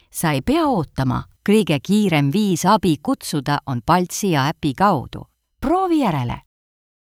Voice Over Artistes- ESTONIAN